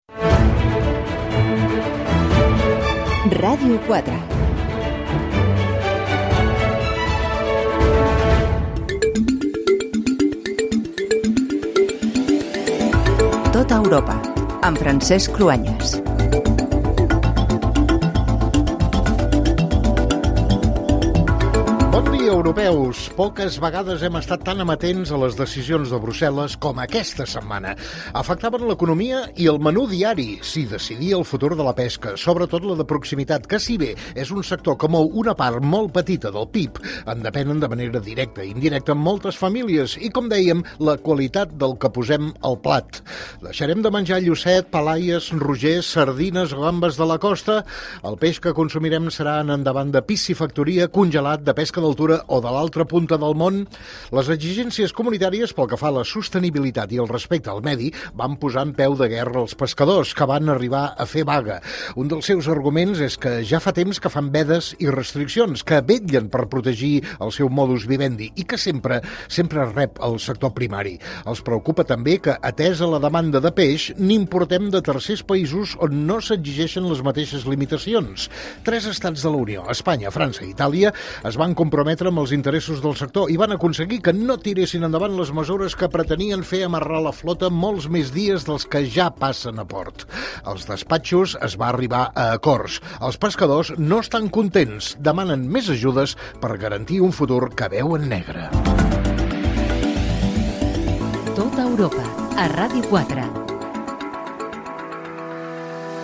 Identificació de la ràdio, careta del programa, pescadors catalans contra les mesures restrictives de pescar imposades per la Unió Europea, indicatiu
Informatiu
FM